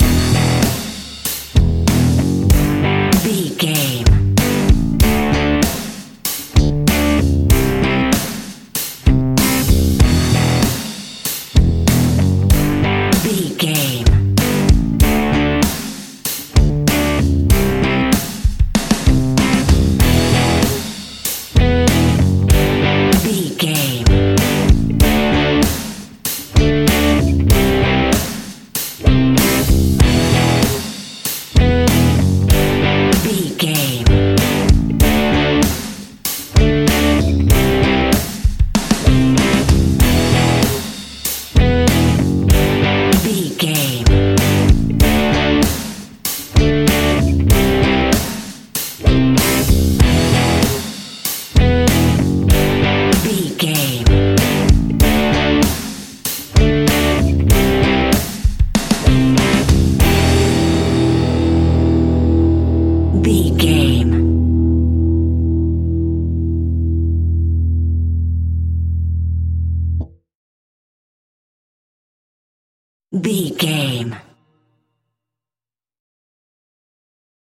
Aeolian/Minor
energetic
driving
happy
bright
electric guitar
bass guitar
drums
hard rock
blues rock
heavy drums
distorted guitars
hammond organ